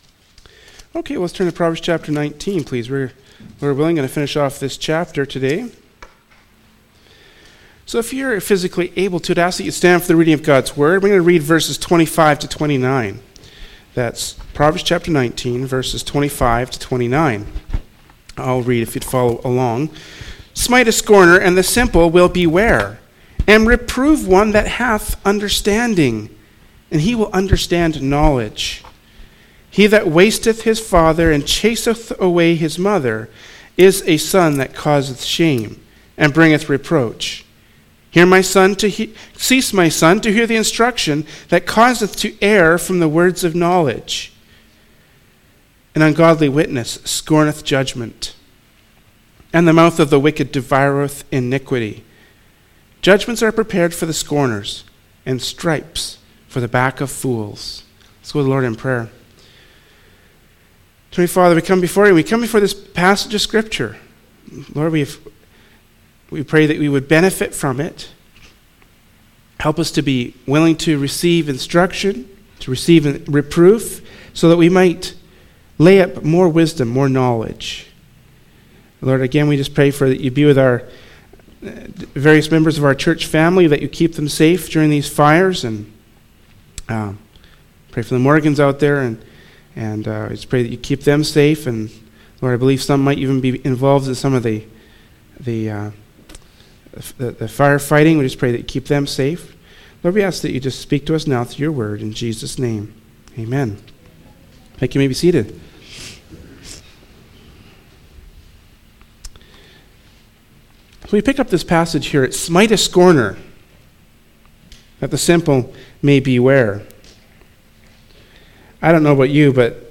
“Proverbs 19:25-29” from Sunday School Service by Berean Baptist Church.
Service Type: Adult Sunday School